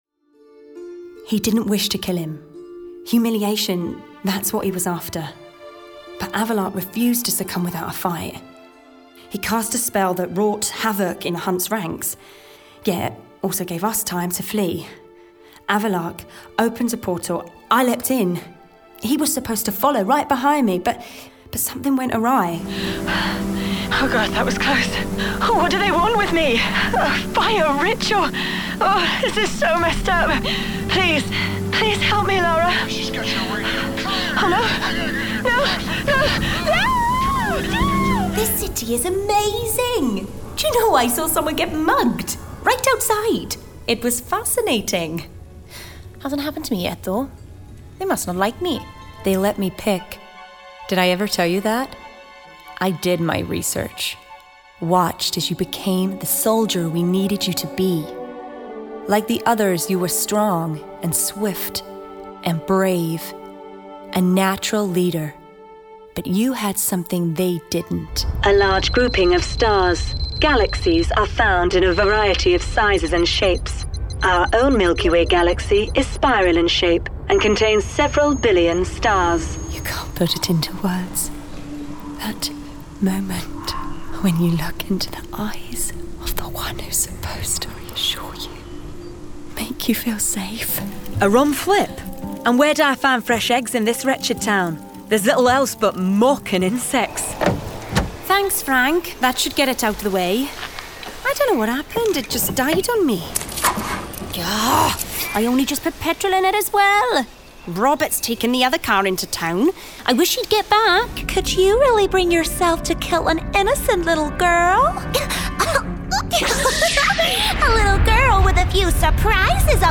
Video Game Showreel
Female
British RP
Neutral British
Bright
Friendly
Confident
Warm